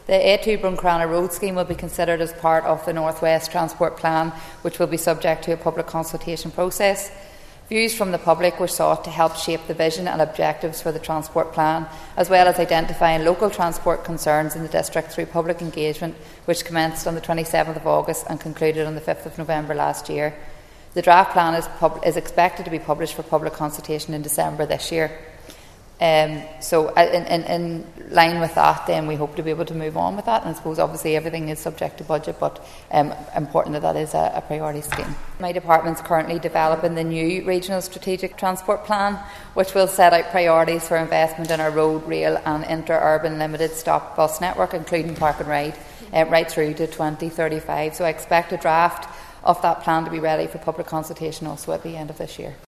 He was speaking after asking Infrastructure Minister Liz Kimmins for an update on the project in the Assembly.